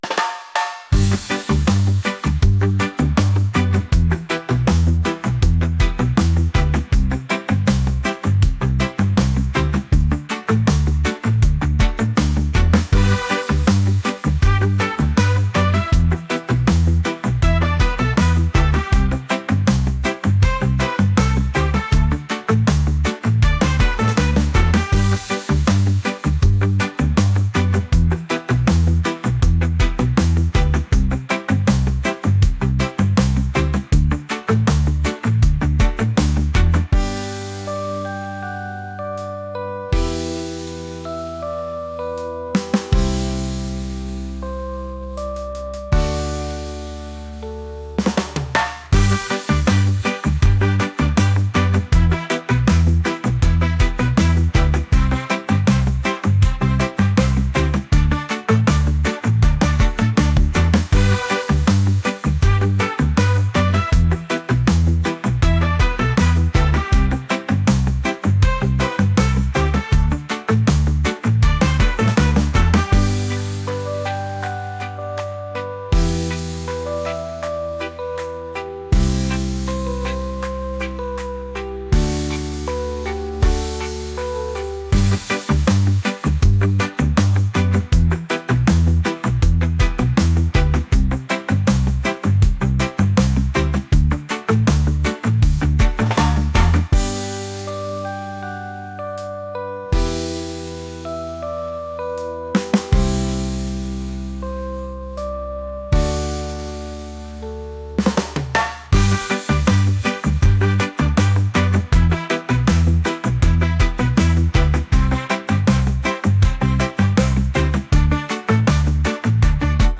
reggae | pop